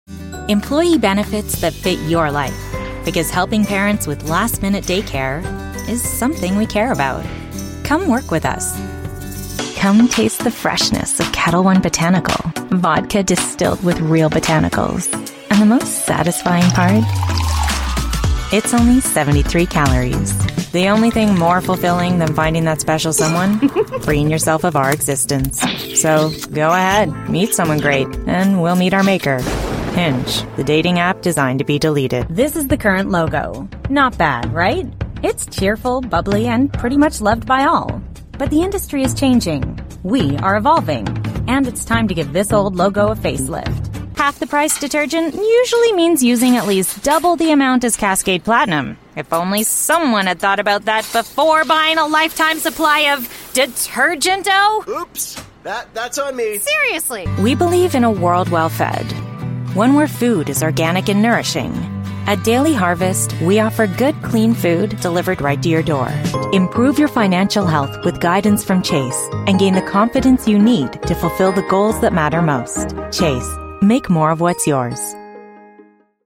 Inglés (Cánada)
Suave
Conversacional
Amistoso